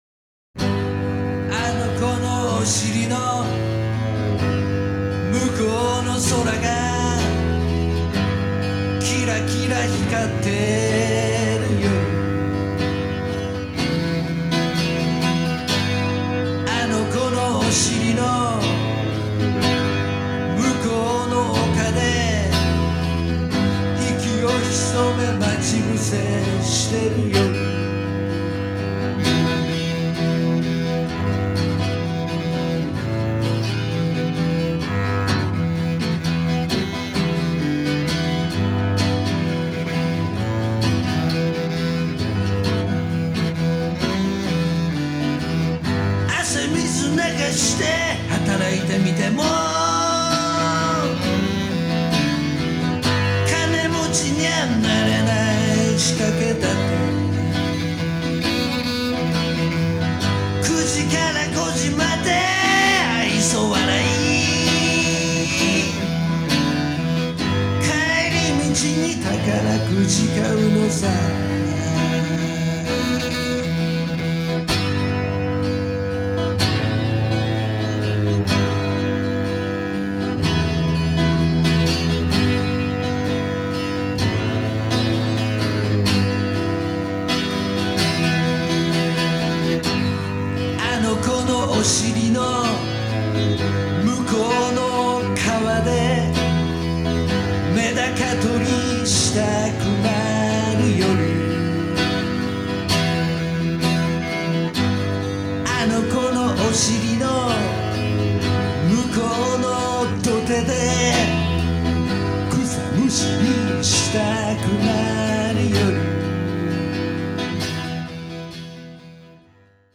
●ヤマハの4chカセットＭＴＲによる一発録音。
チェロとギターとヴォーカルの微妙なからみと、目の醒めるような言葉の世界は、まさに前代未聞である。